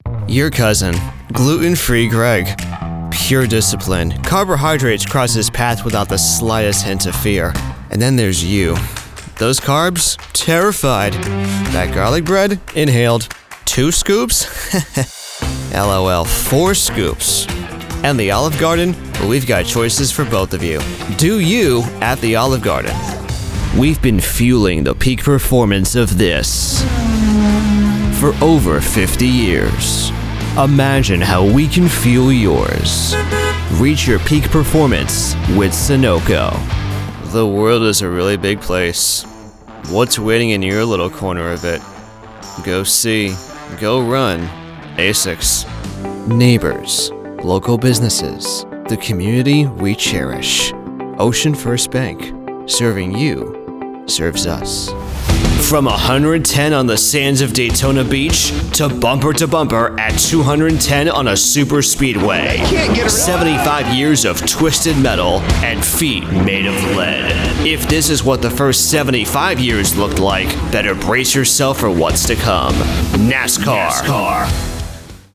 Commercial Demo
English-North American, English-Neutral
Young Adult